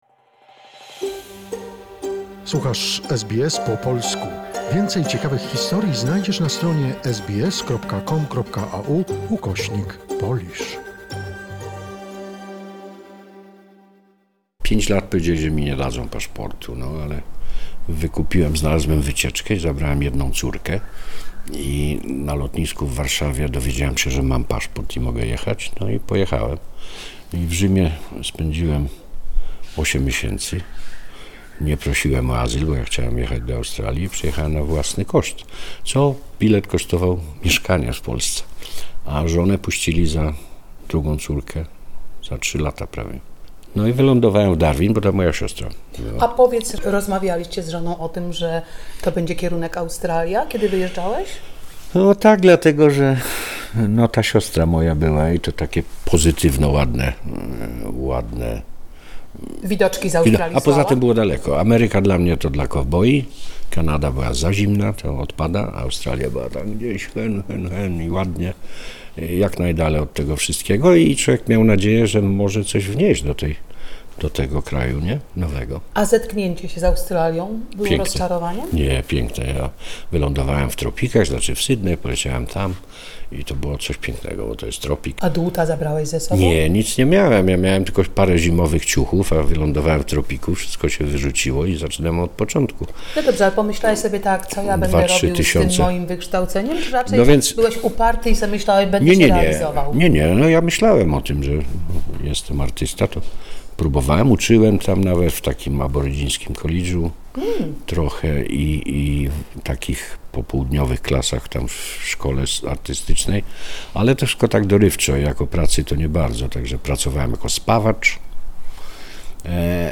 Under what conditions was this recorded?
In the workshop of a sculptor and medal designer ...